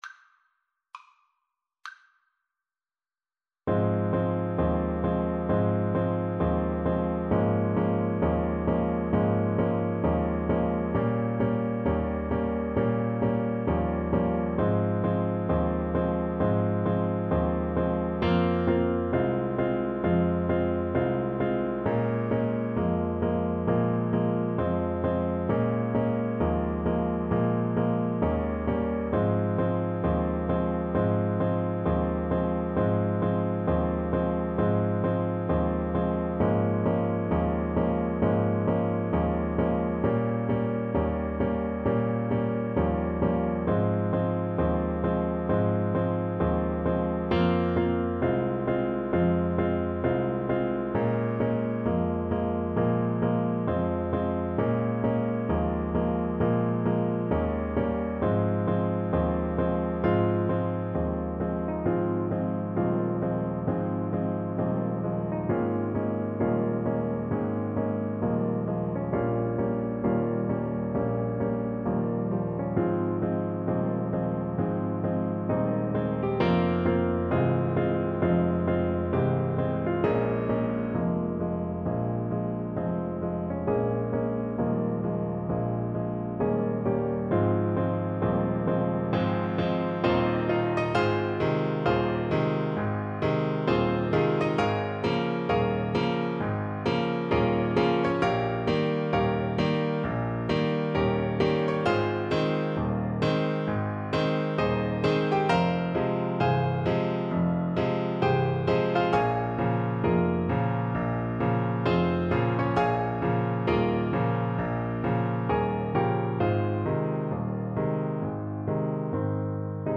Alto Saxophone
2/2 (View more 2/2 Music)
Blues Tempo (=66)
Jazz (View more Jazz Saxophone Music)